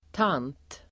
Ladda ner uttalet
tant substantiv (även som tilltalsord), aunt , lady Uttal: [tan:t] Böjningar: tanten, tanter Synonymer: dam, gumma, kvinna Definition: (äldre) dam, kvinna; faster/moster Exempel: en gammal tant (an old lady), tant Sigrid (aunt Sigrid)